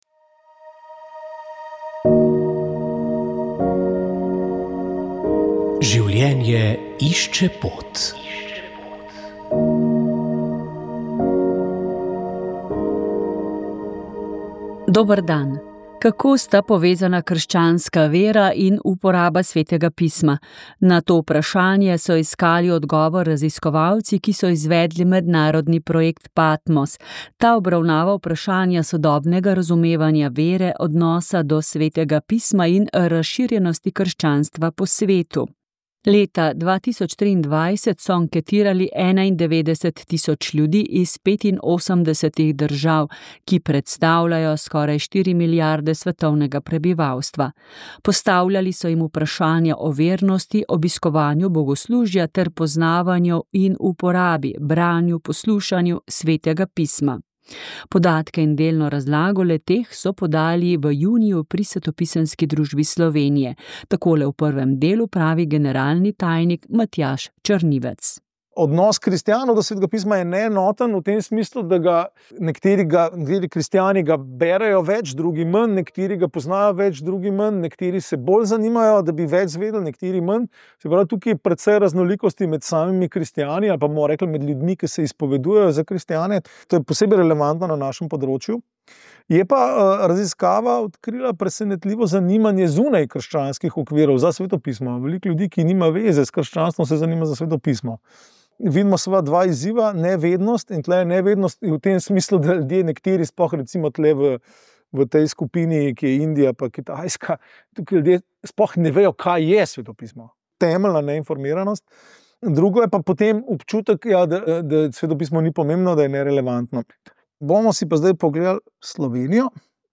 19. decembra je sedem večjih verskih skupnosti v Sloveniji podpisalo skupno izjavo glede uvajanja samomora s pomočjo v slovensko zakonodajo. to je dobrodošel prispevek civilne družbe, da bi predlog Srebrne niti končal parlamentarno pot in bi začeli s širšim družbenim pogovorom. Tokrat smo prisluhnili predsedniku slovenske škofovske konference dr. Andreju Sajetu.